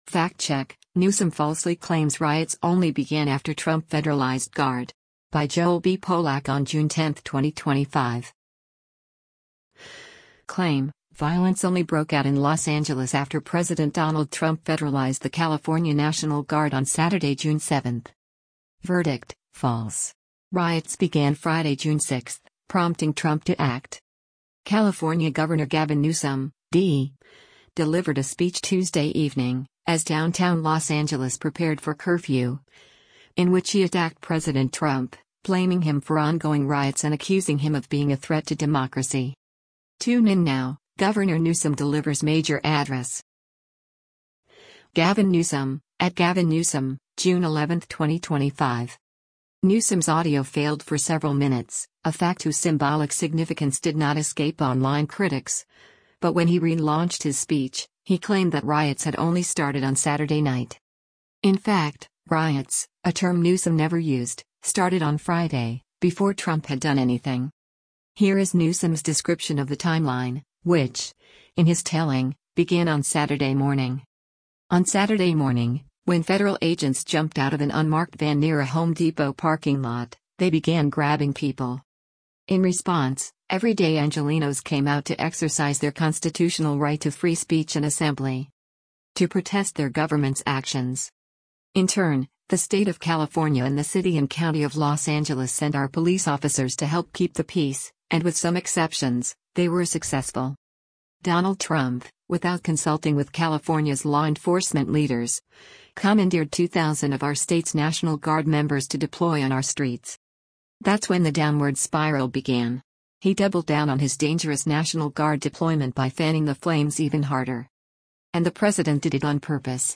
California Gov. Gavin Newsom (D) delivered a speech Tuesday evening, as downtown Los Angeles prepared for curfew, in which he attacked President Trump, blaming him for ongoing riots and accusing him of being a threat to democracy.
Newsom’s audio failed for several minutes — a fact whose symbolic significance did not escape online critics — but when he re-launched his speech, he claimed that riots had only started on Saturday night.